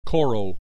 click this icon to hear the preceding term pronounced in China and areas of Southeast Asia where Chinese culture has diffused (especially Vietnam, Malaysia, and Singapore).
koro.mp3